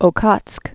(ō-kŏtsk, ə-ôtsk), Sea of